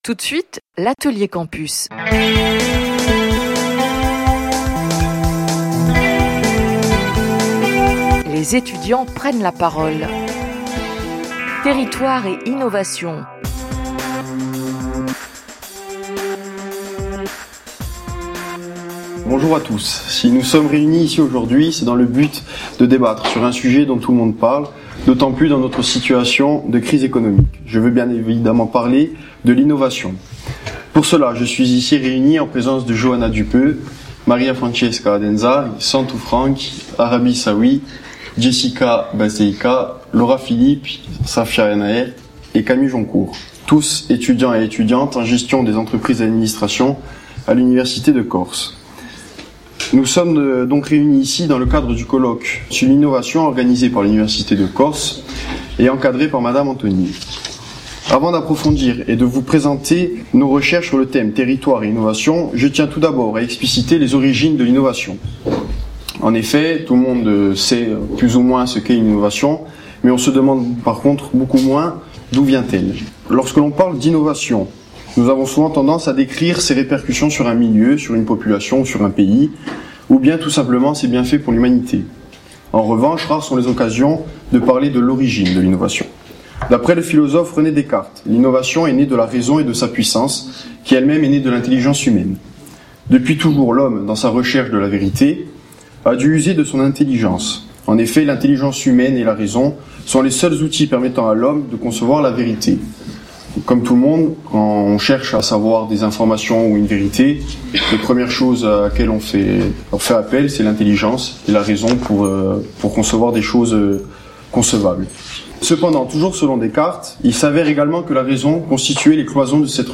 Vous pourrez également écouter le débat radiophonique qui confronte les idées et les avis de notre sujet : la pensée du territoire par les chercheurs de l’Université de corse, les différentes formes d’innovations en corse, comment, devant un territoire singulier et hostile à l’industrialisation, la Corse cherche-t-elle à s'imposer face à l'innovation et comment réconcilier les Corses avec le développement et l’innovation de leur cher territoire ?
debat_territoire.mp3